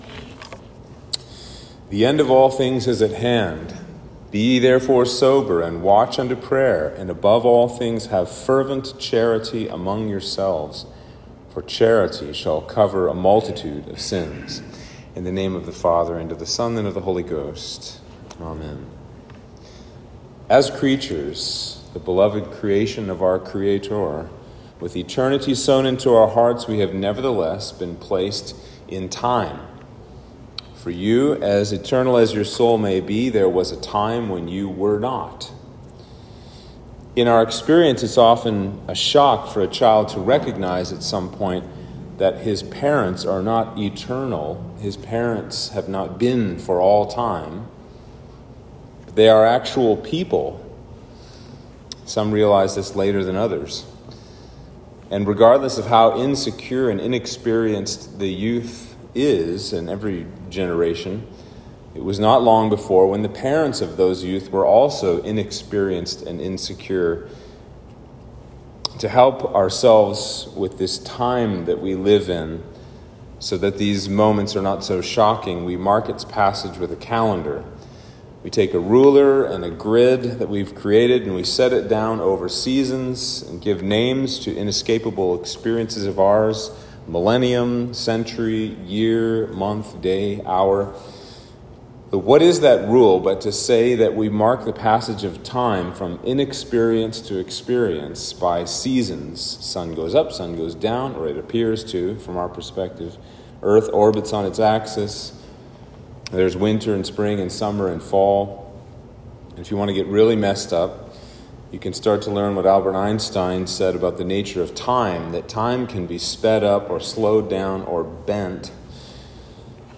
Sermon for Sunday After Ascension